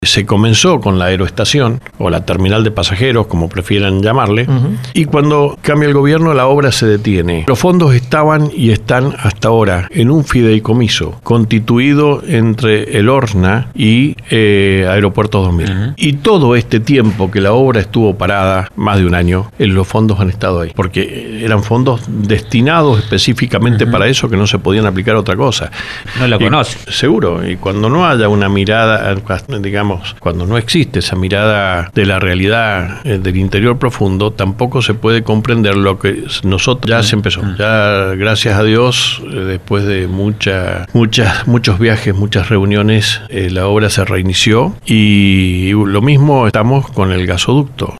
La falta de acompañamiento del Estado nacional, su economía, la autonomía municipal y la modernización del Estado fueron algunos de los temas sobre los que habló Omar Félix -intendente de San Rafael- en LV18, apenas unas horas más tarde de su discurso de apertura de sesiones ordinarias del Concejo Deliberante.